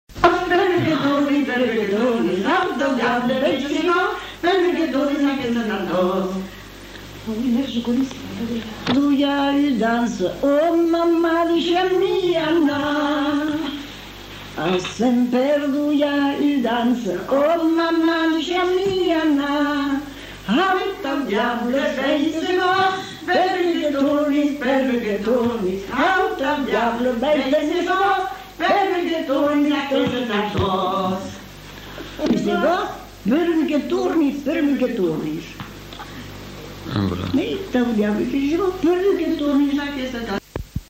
Lieu : Mont-de-Marsan
Genre : chant
Effectif : 2
Type de voix : voix de femme
Production du son : chanté
Danse : rondeau